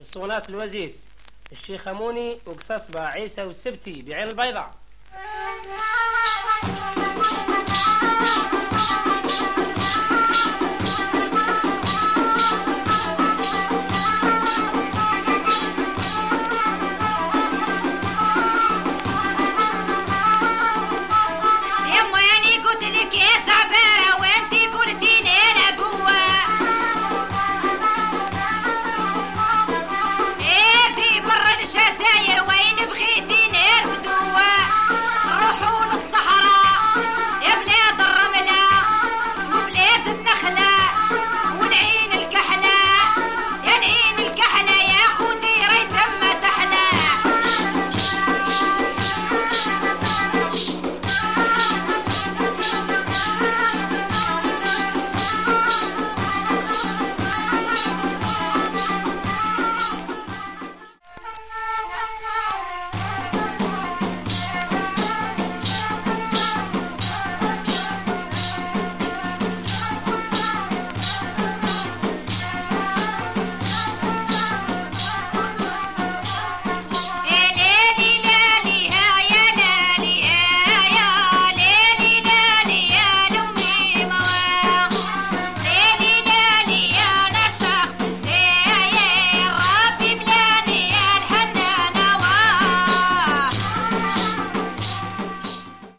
Rough Algerian female chant.